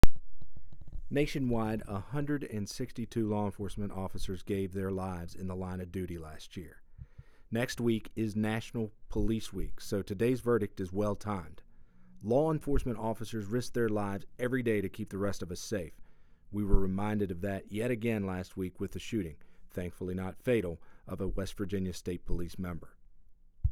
Click on the links below to listen to an audio sound bite from U.S. Attorney Goodwin regarding today’s trial verdict: